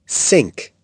SINK.mp3